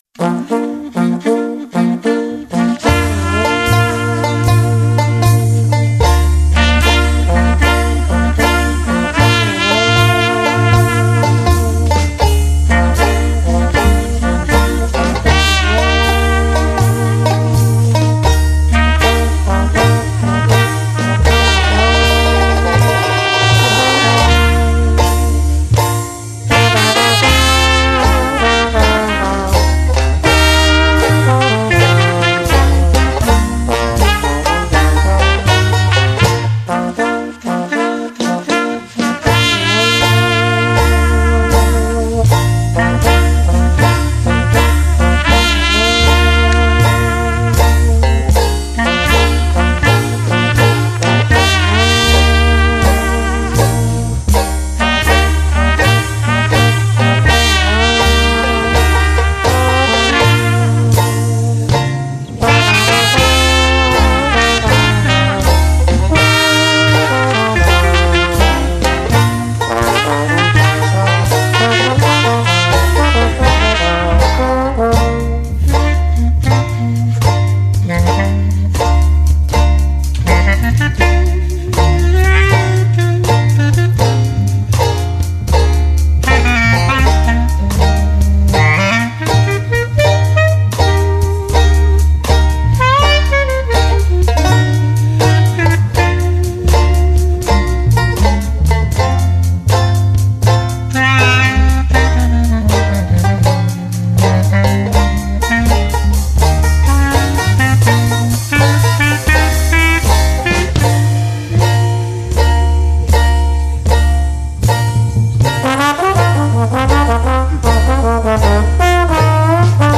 Dixieland